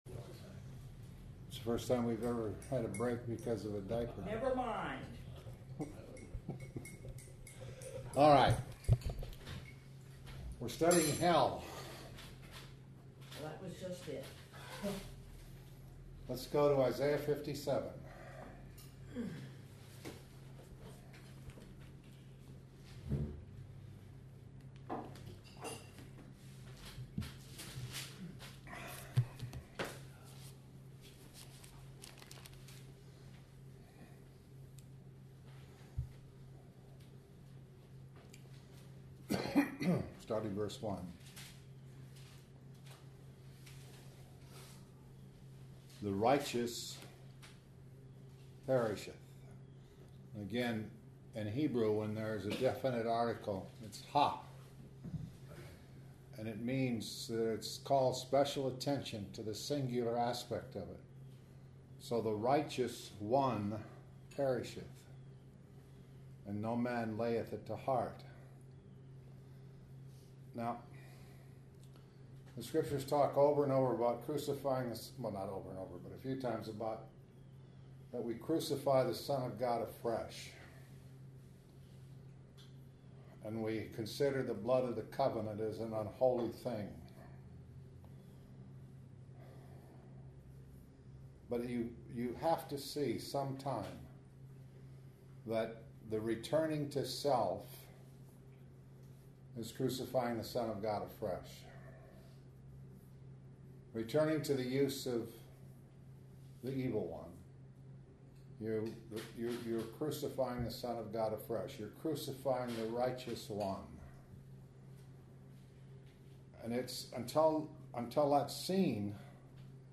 ← Nov. 6, 2013 – Luke 12:4 Nov. 8, 2013 – Luke 12:5 → Nov. 7, 2013 – Luke 12:4 Posted on December 18, 2013 by admin Nov. 7, 2013 – Luke 12:4 Isaiah 57:1-21 Matthew 11:28-30 This entry was posted in Morning Bible Studies .